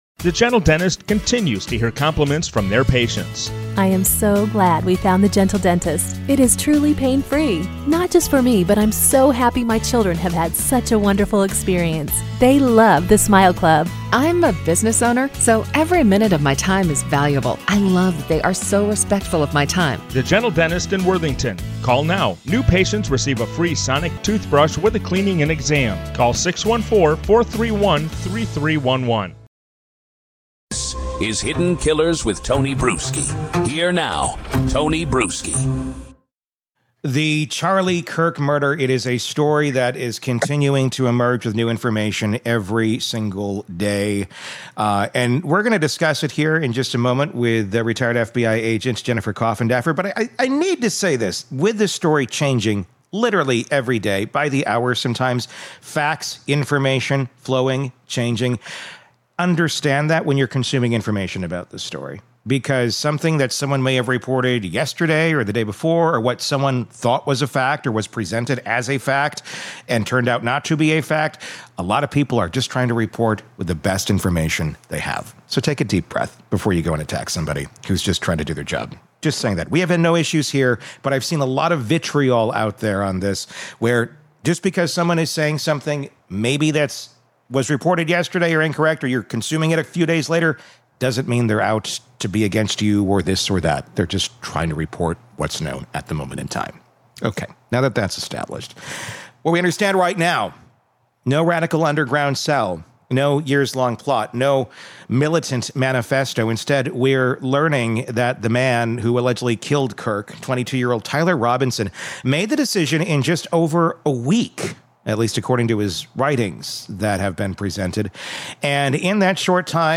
True Crime Today | Daily True Crime News & Interviews / "I Had the Opportunity”: What Else Charlie Kirk’s Alleged Assassin Wrote Before Pulling the Trigger!